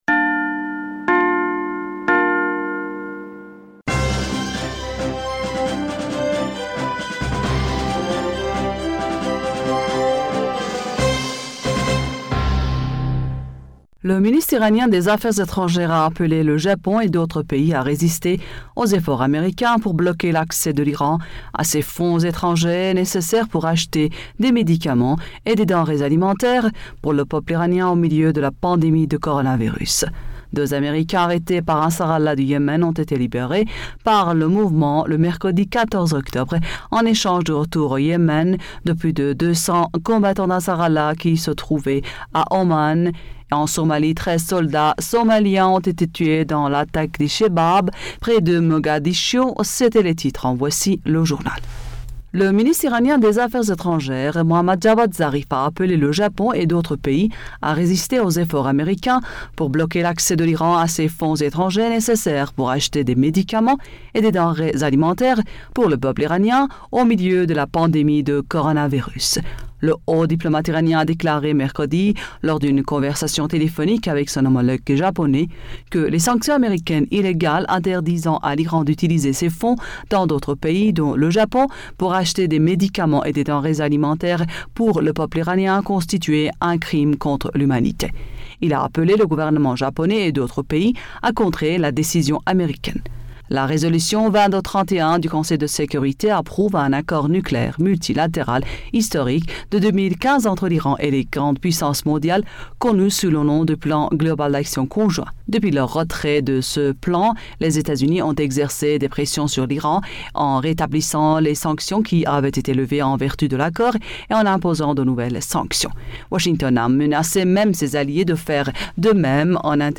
Bulletin d'information du 15 Octobre 2020